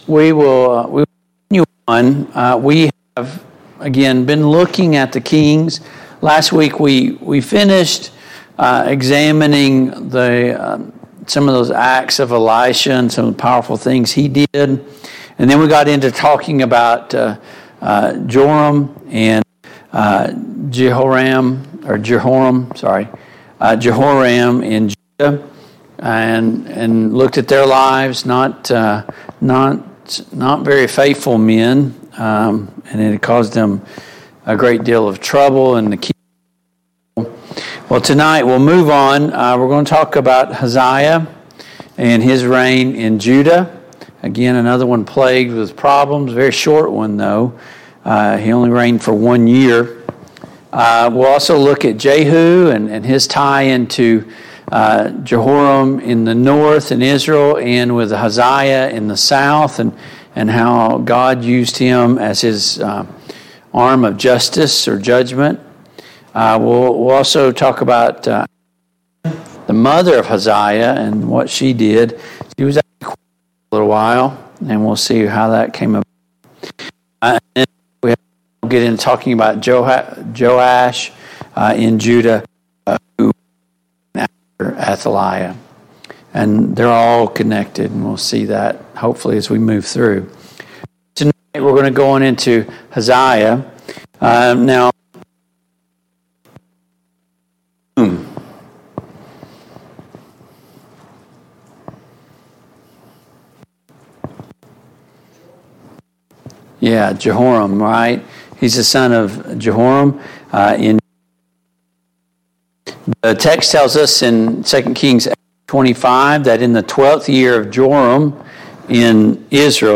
The Kings of Israel Passage: 2 Kings 8, 2 Kings 9, 2 Chronicles 22, 2 Chronicles 23 Service Type: Mid-Week Bible Study